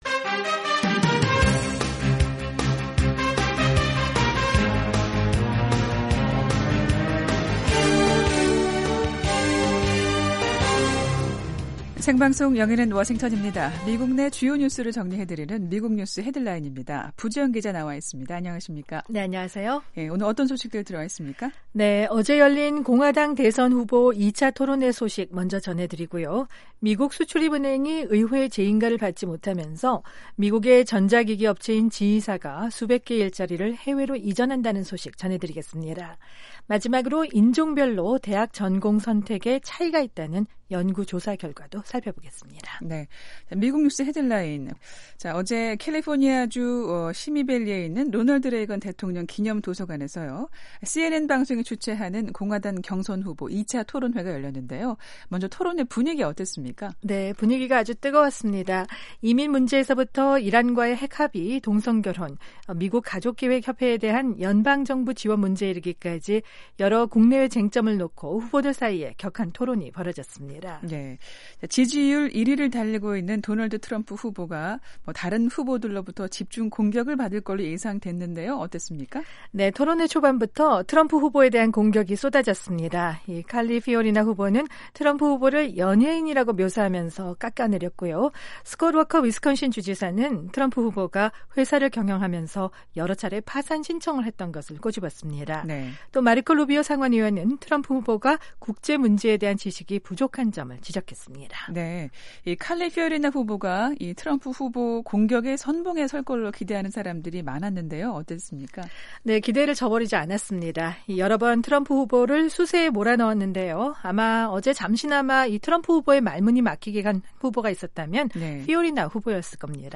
미국 내 주요 뉴스를 정리해 드리는 ‘미국 뉴스 헤드라인’입니다. 16일 열린 공화당 대선 후보 2차 토론회 소식 먼저 전해 드리고요. 미국 수출입은행이 의회 재인가를 받지 못하면서 미국의 전자기기 업체인 GE가 수백 개의 일자리를 해외로 이전한다는 소식 전해 드립니다.